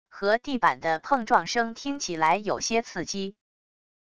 和地板的碰撞声听起来有些刺激wav音频